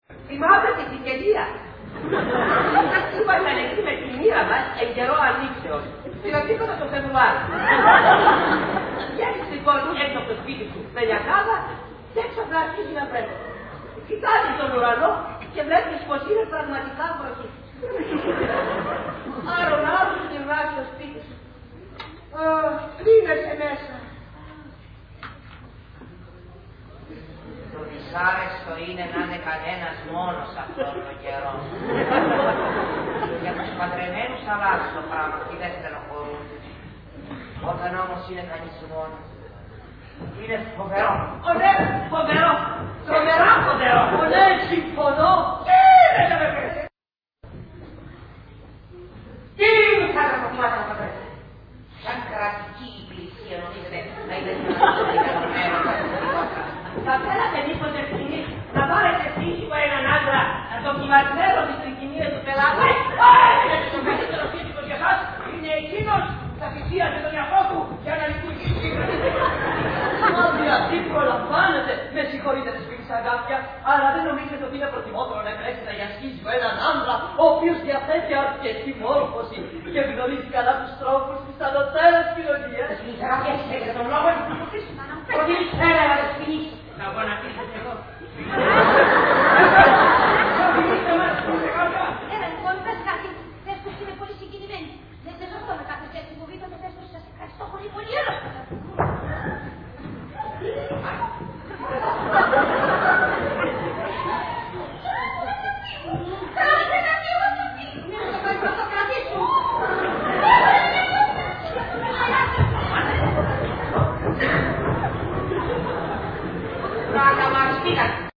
Ηχογράφηση Παράστασης
Αποσπάσματα από την παράσταση
sound δείγμα, διάρκεια 00:01:58, ΑΝΟΥΤΣΚΙΝ, ΖΕΒΑΚΙΝ, ΣΒΟΥΓΓΑΤΟΣ, ΚΑΤΣΚΟΡΙΩΦ